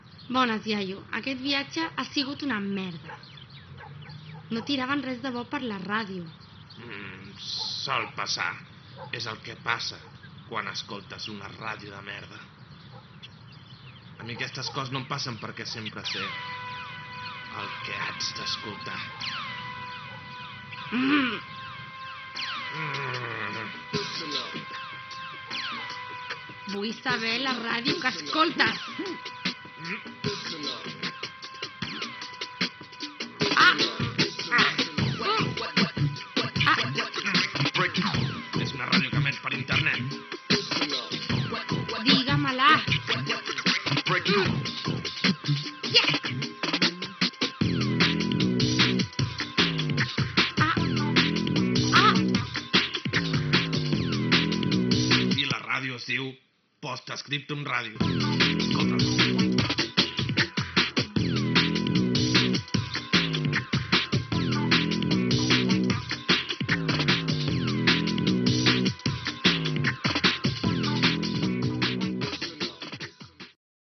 f1a949bb3860a3289e036699ba8511baeffd5faa.mp3 Títol Post Scriptum Ràdio Emissora Post Scriptum Ràdio Titularitat Tercer sector Tercer sector Lliure Descripció Promoció de l'emissora, amb indicatiu al final.